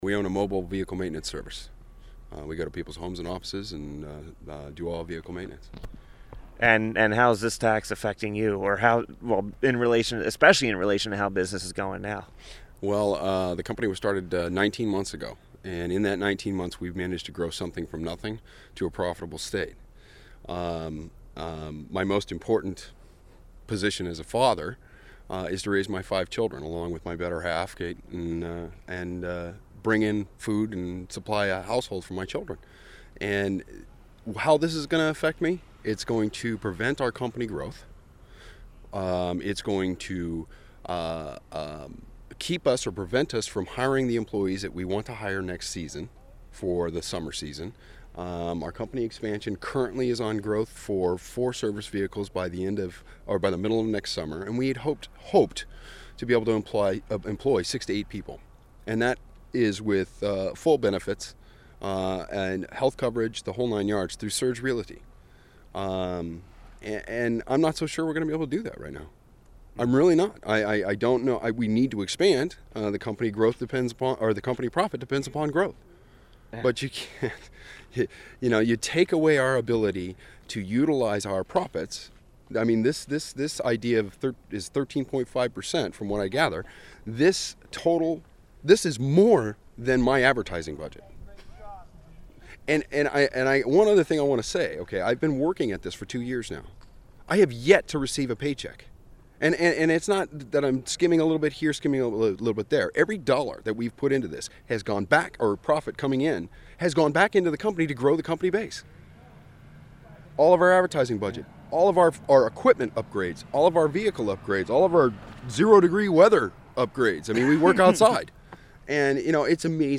The following audio cuts are interviews I did 12/16/09 at the LLC Tax hearing in Concord and played on the show: